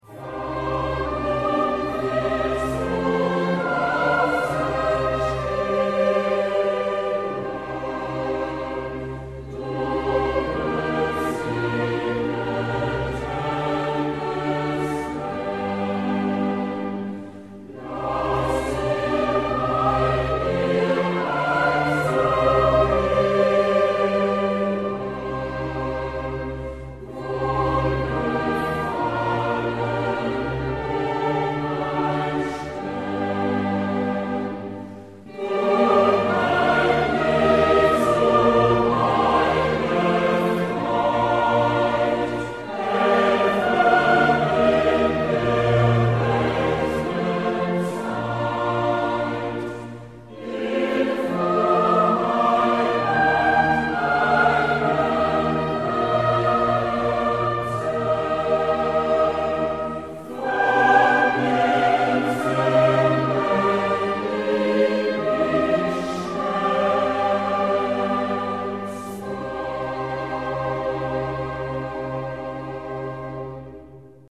Stimmen - MIDI / mp3 (Chor) mp3